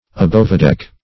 Search Result for " abovedeck" : The Collaborative International Dictionary of English v.0.48: Abovedeck \A*bove"deck`\, a. On deck; and hence, like aboveboard, without artifice.